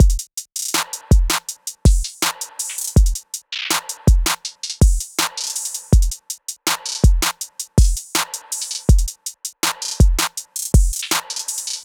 SOUTHSIDE_beat_loop_bread_full_02_162.wav